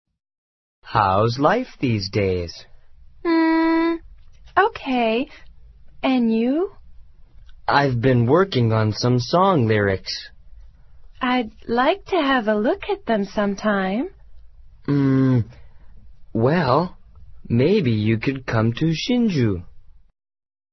演奏会上 赫伯特跟柔丝说话